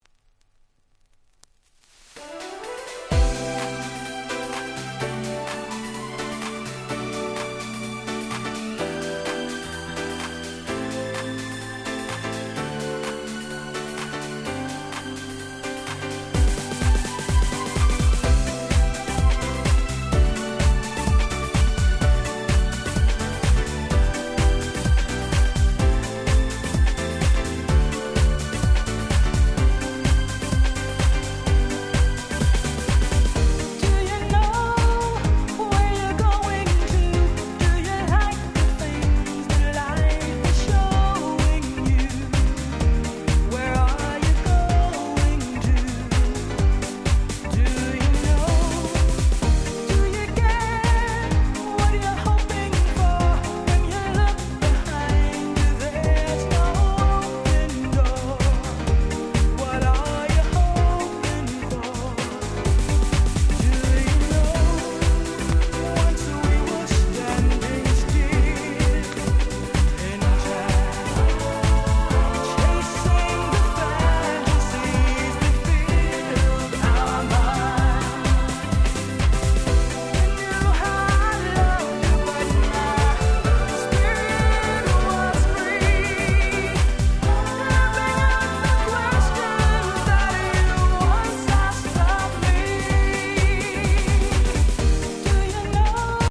【Media】Vinyl 12'' Single
98' Japanese House Classics !!